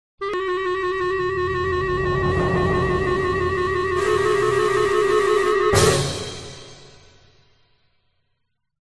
Play Musica Suspence, Download and Share now on SoundBoardGuy!